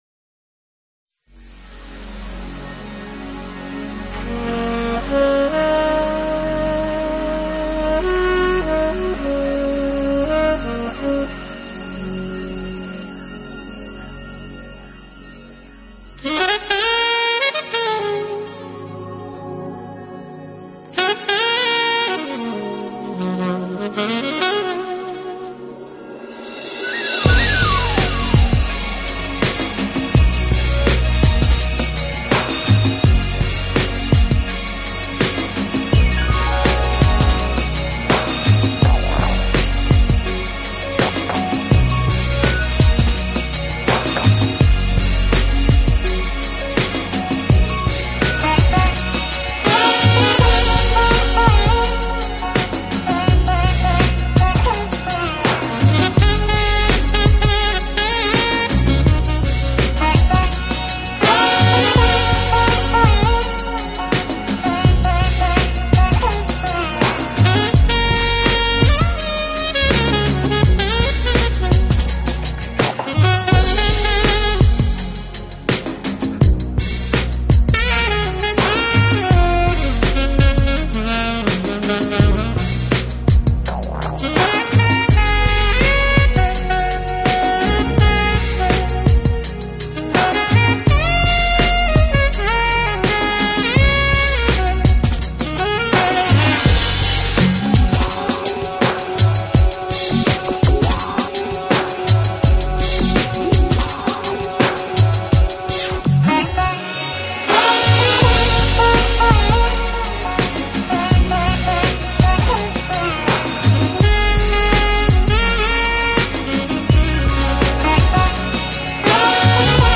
【爵士萨克斯】